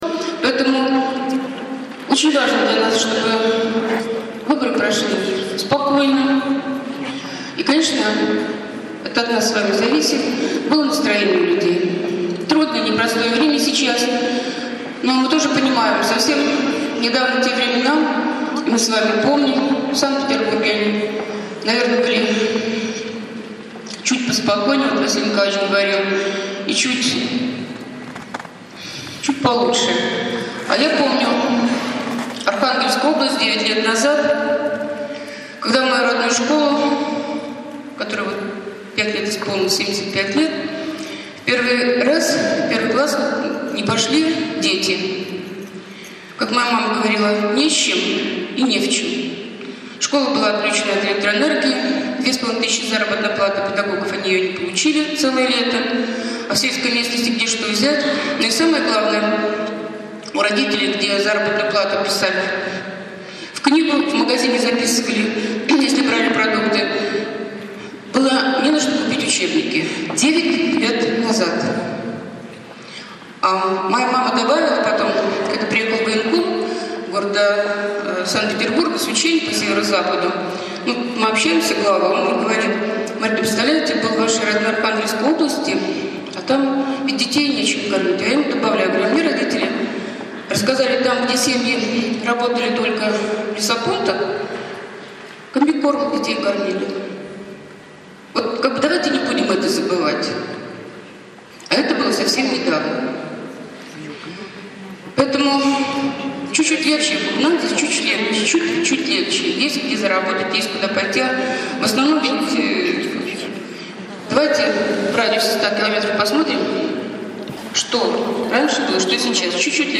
Поводом послужило выступление Щербаковой в помещении школы №222, где 15 ноября прошла ее  встреча с директорами школ, методистами и заведующими детских садов, на которой велась «мягкая» предвыборная агитация.